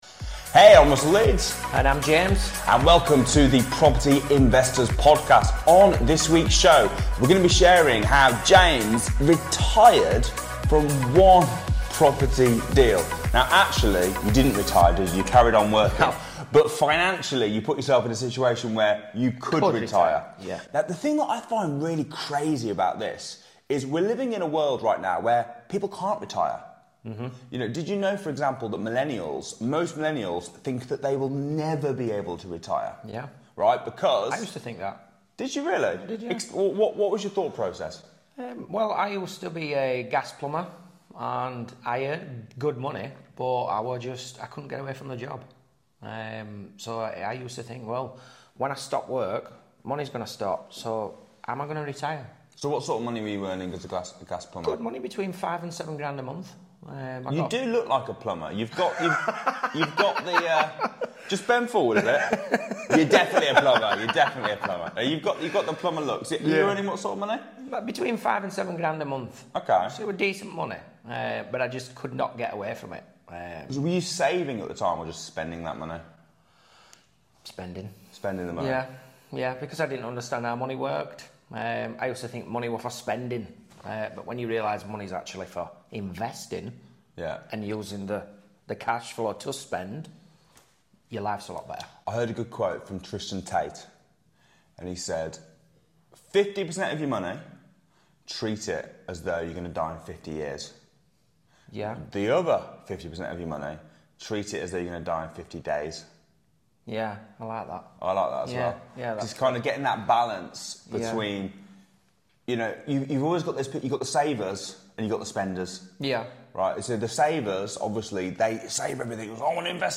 Join us on this week's episode of The Property Investors Podcast for an enlightening and engaging conversation